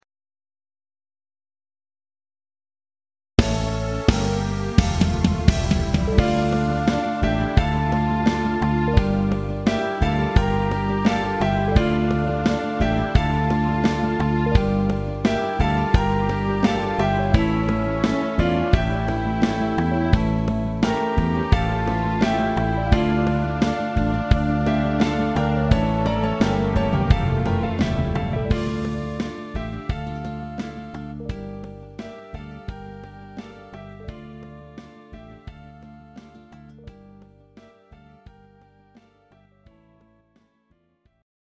Greek Ballad